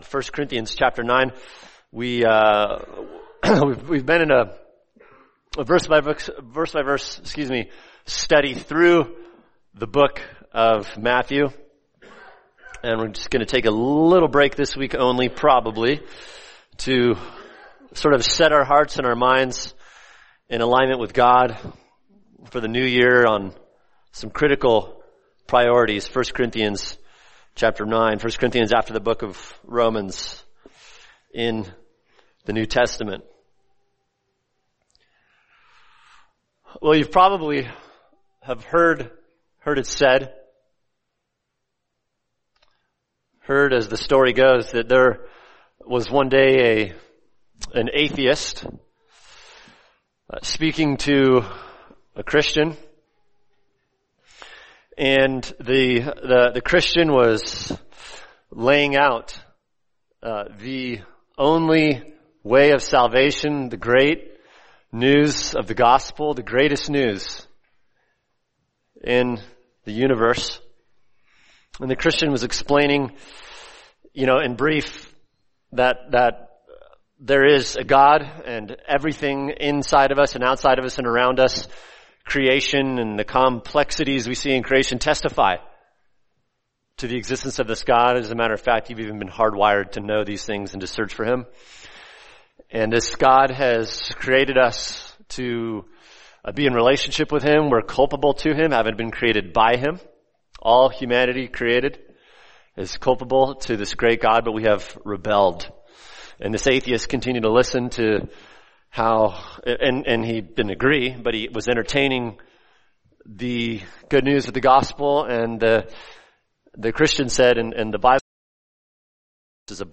[sermon] 1 Corinthians 9:19-22 – Reaching the Lost | Cornerstone Church - Jackson Hole